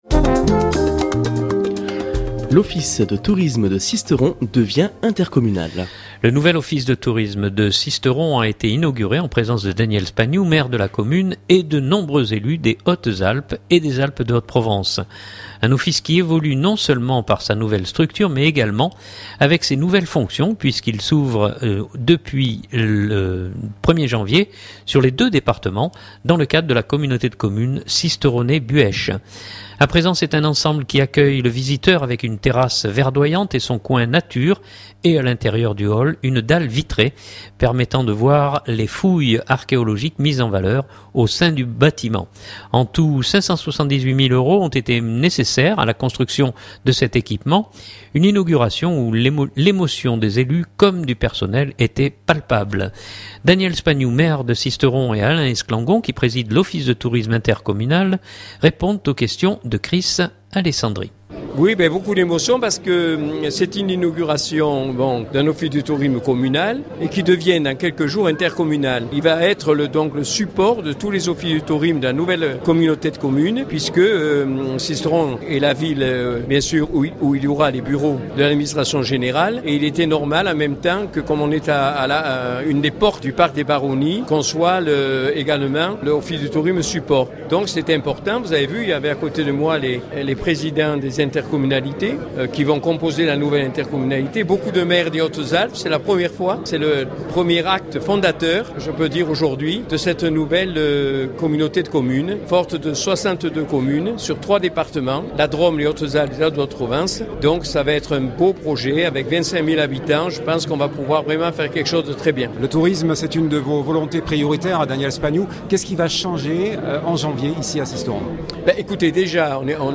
Une inauguration où l’émotion des élus comme du personnel était palpable.